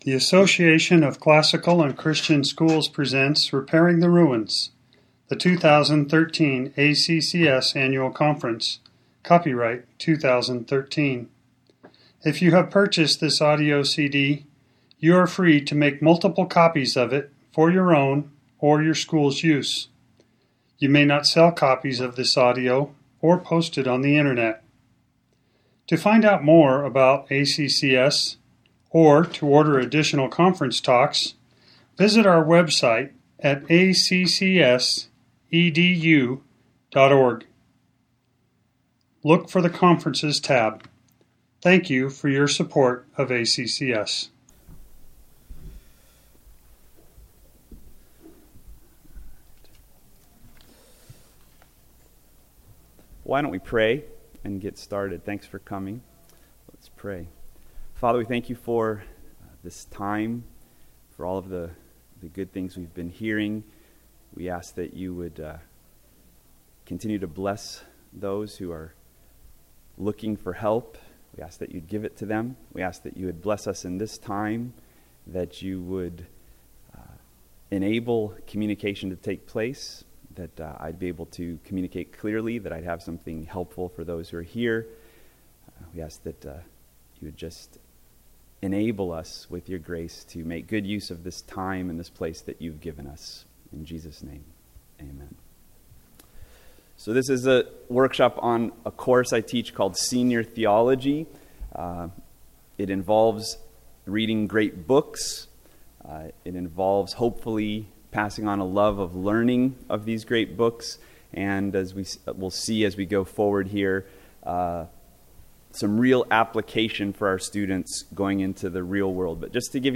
2013 Workshop Talk | 1:00:43 | 7-12, Literature, Theology & Bible
The Association of Classical & Christian Schools presents Repairing the Ruins, the ACCS annual conference, copyright ACCS.